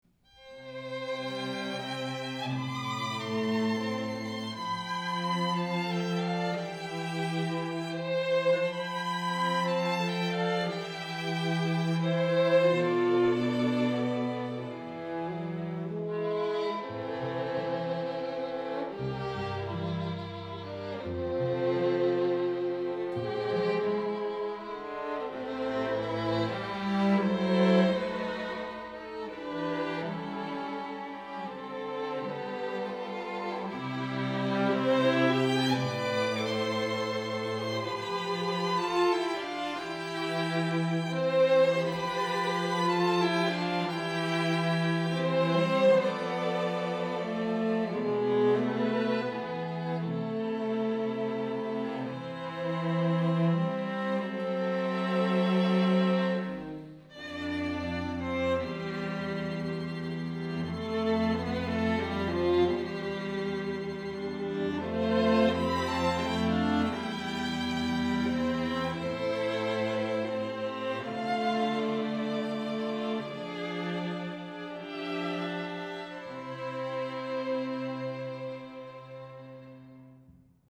Quatuor
Musique de film